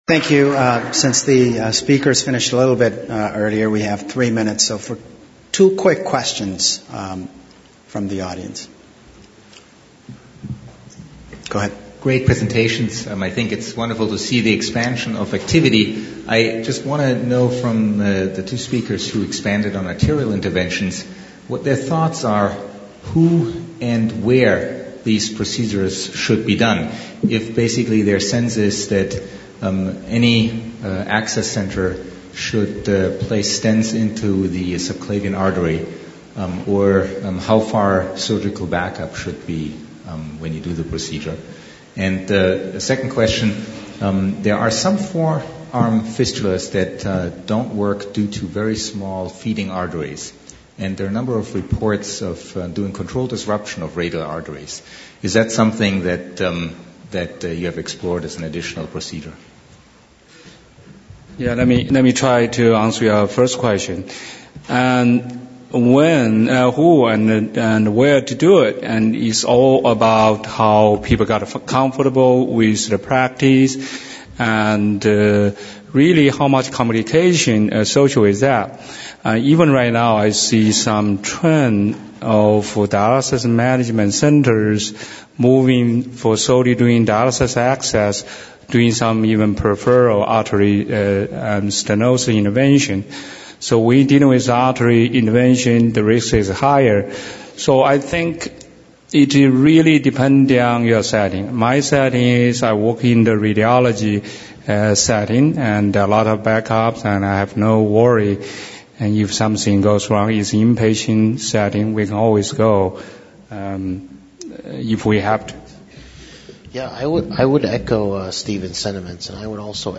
HDCN-- 2011 ASDIN Annual Scientific Meeting
Discussions